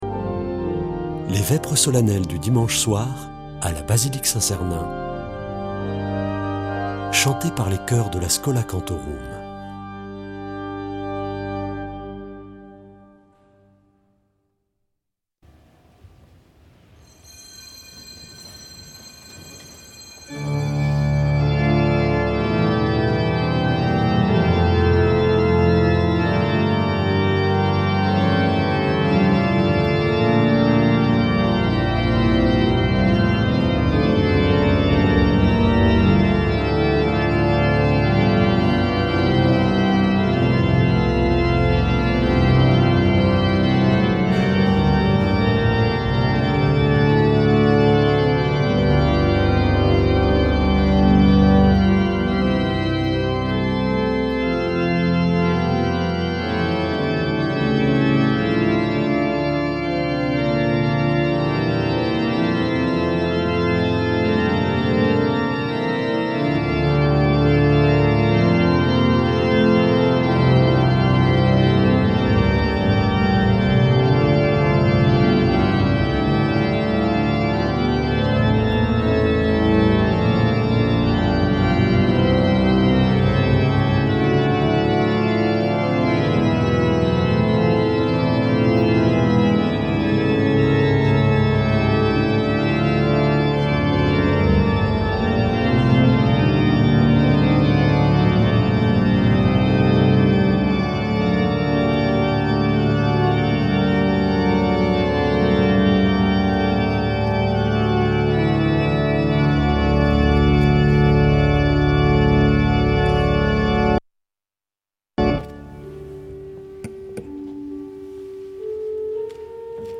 Vêpres de Saint Sernin du 24 mars
Schola Saint Sernin Chanteurs